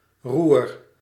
The Roer (Dutch: [ruːr]
Nl-Roer.ogg.mp3